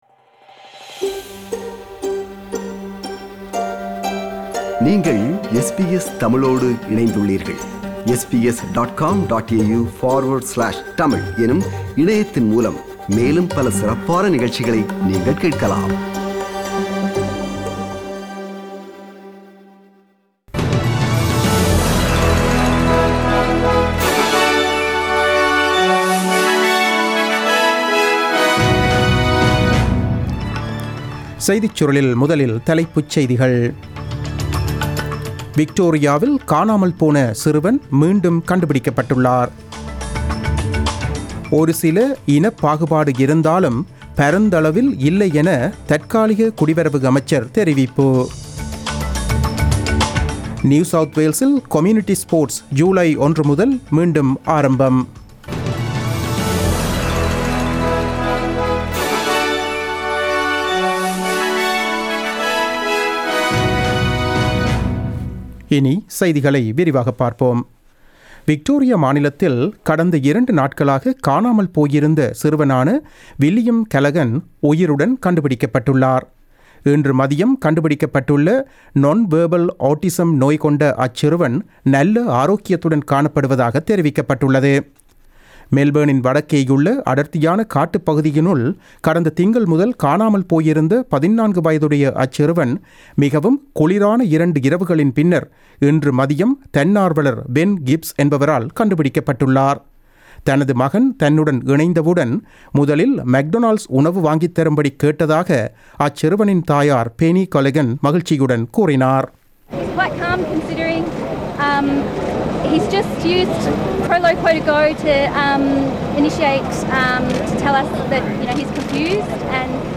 The news bulletin broadcasted on 10 June 2020 at 8pm.